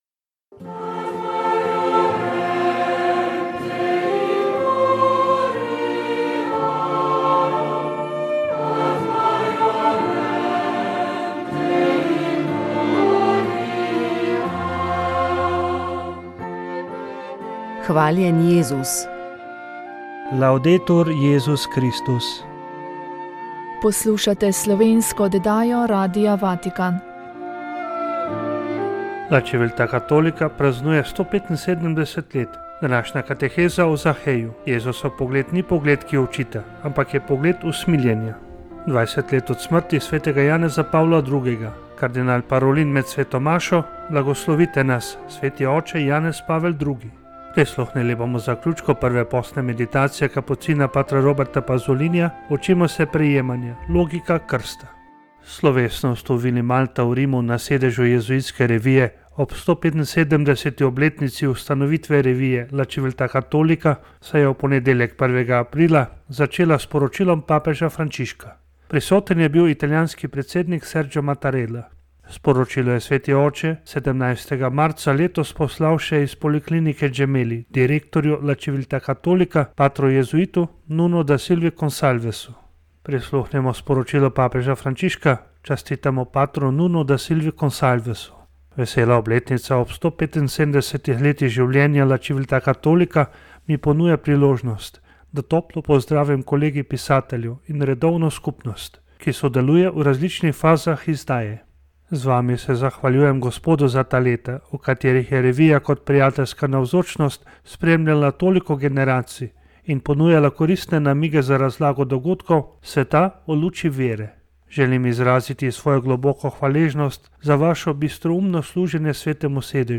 V oddaji Moja zgodba bomo predstavili dve razmišljani z znanstvenega posveta z naslovom Ob 100. obletnici Gentilejeve šolske reforme: posledice za primorski prostor, ki sta ga organizirala Študijski center za narodno spravo in Društvo slovenskih izobražencev in je potekal septembra 2023 v Trstu.